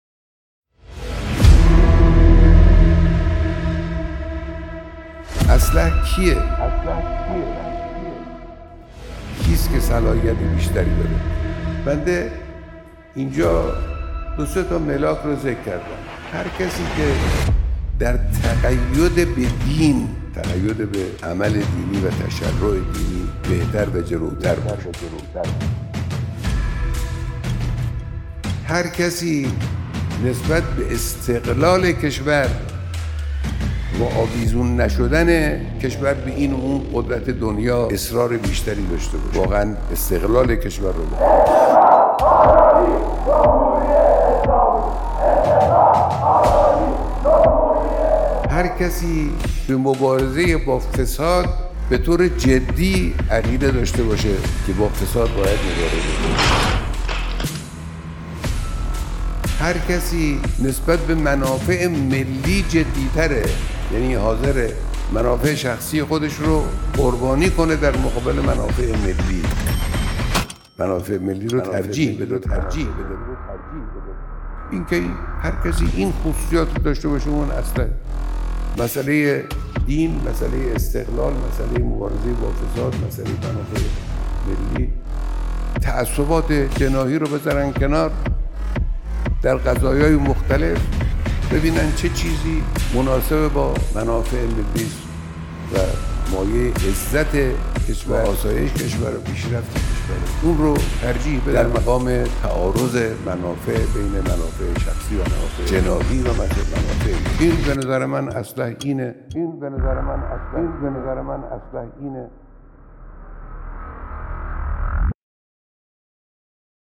در آستانه برگزاری انتخابات دوازدهمین دوره مجلس شورای اسلامی و ششمین دوره مجلس خبرگان رهبری، جمعی از رأی اولی‌ها و خانواده‌های معظم شهدا، صبح روز چهارشنبه با حضور در حسینیه امام خمینی(ره)، با حضرت آیت‌الله خامنه‌ای رهبر انقلاب اسلامی دیدار کردند. در ادامه بخشی از سخنان رهبر معظم انقلاب اسلامی را بشنوید.